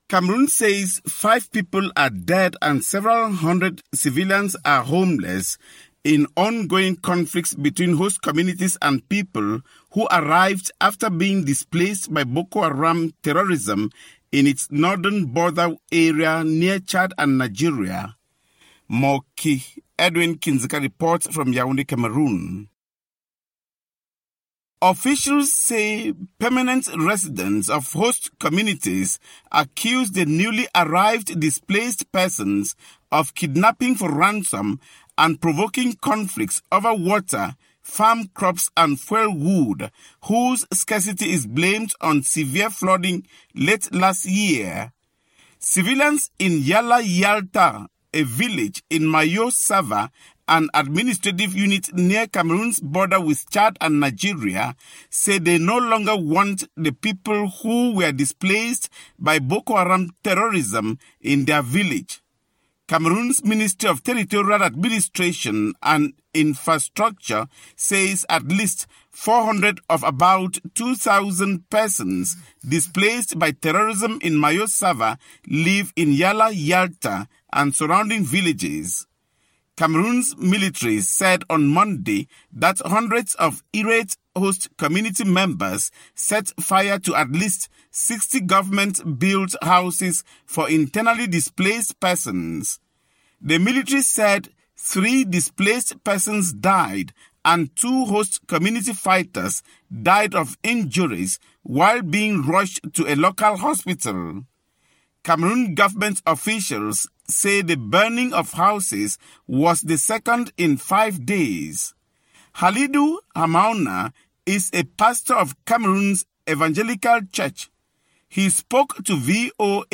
reports from Yaounde